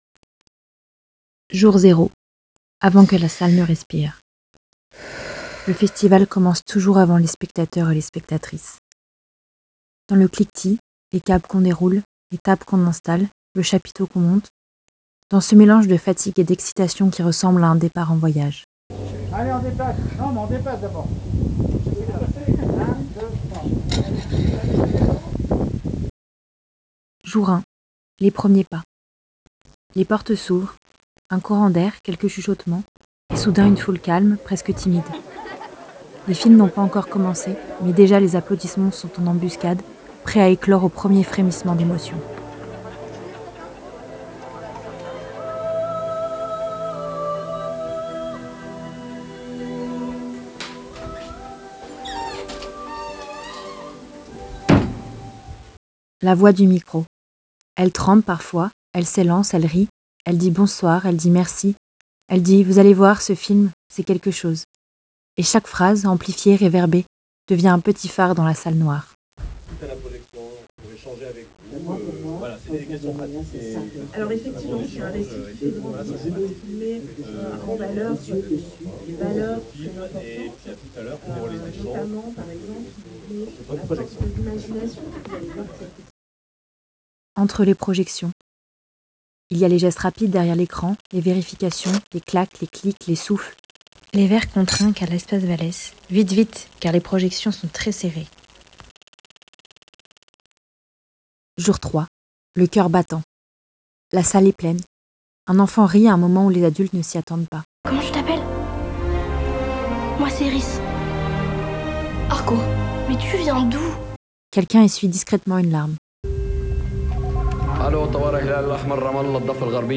Pour vous replonger dans cette édition, laissez vous immerger dans cette création poétique et sonore.